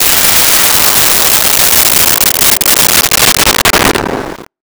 Missle 04
Missle 04.wav